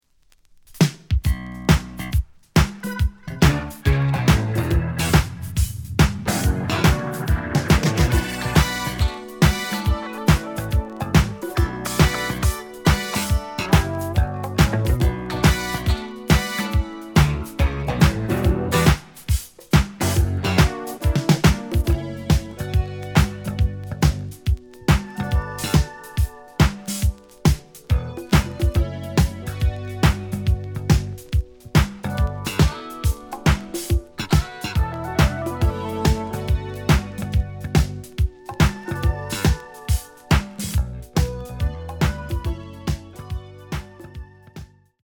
The audio sample is recorded from the actual item.
●Format: 7 inch
●Genre: Soul, 80's / 90's Soul